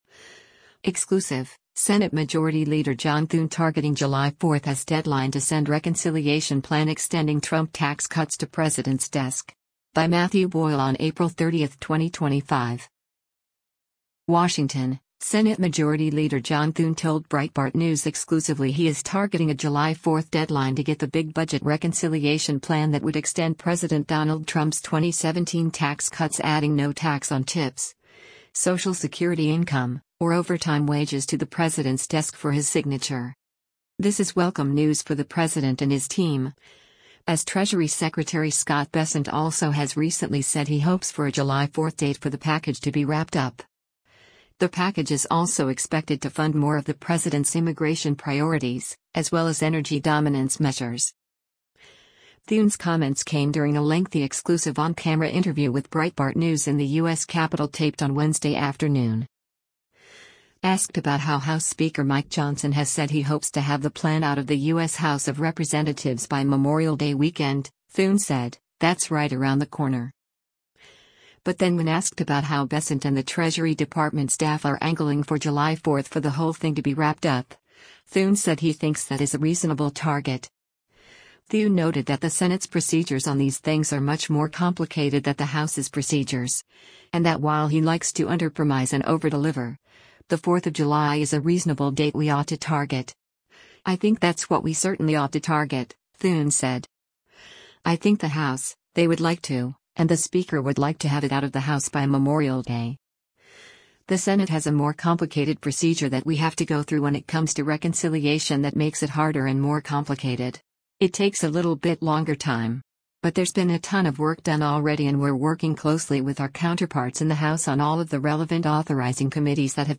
Thune’s comments came during a lengthy exclusive on-camera interview with Breitbart News in the U.S. Capitol taped on Wednesday afternoon.